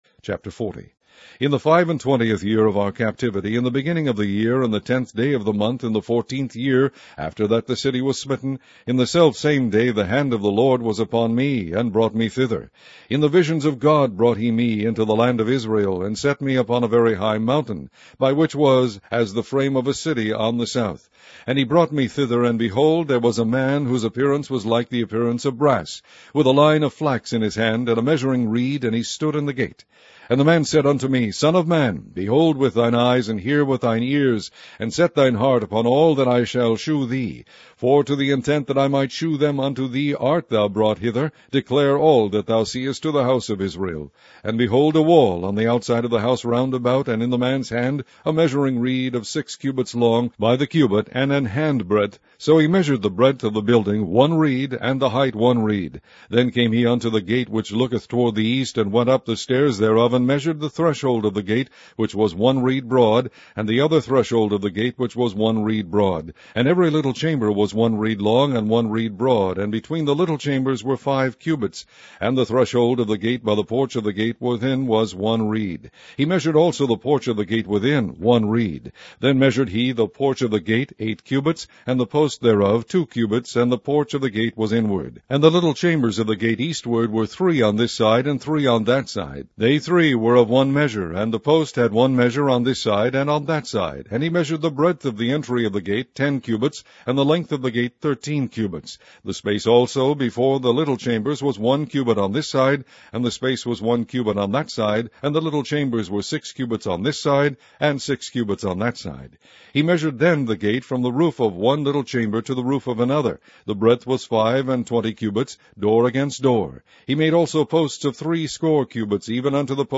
Online Audio Bible - King James Version - Ezekiel